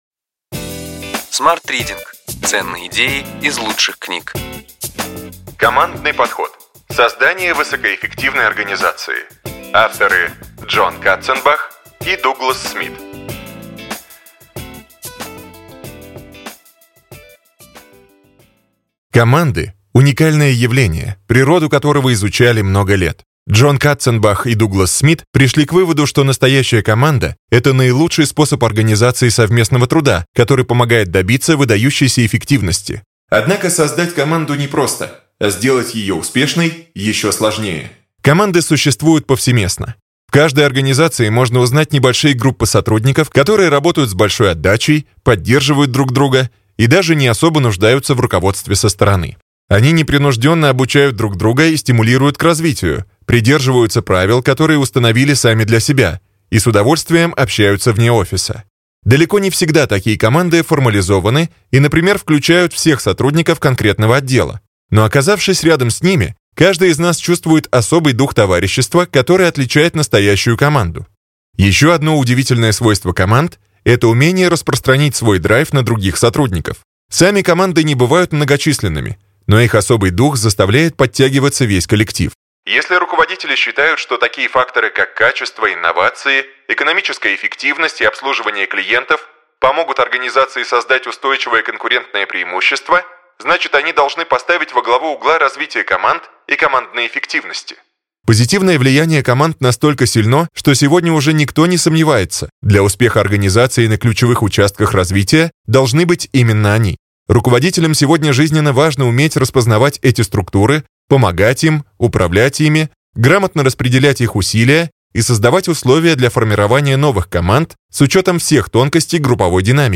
Аудиокнига Ключевые идеи книги: Командный подход. Создание высокоэффективной организации.